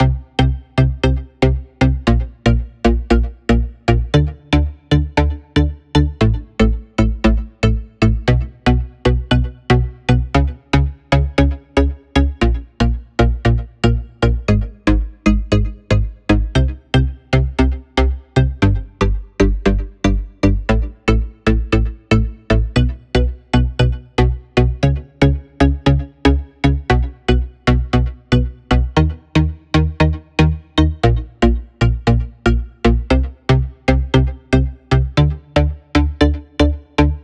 117 BPM Beat Loops Download